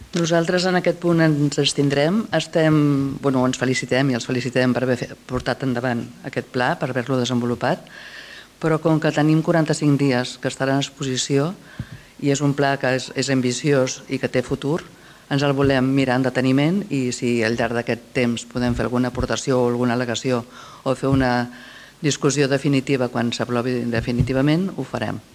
PLe Municipal. Juliol de 2025
Àngels Cardona, regidora Movem Martorell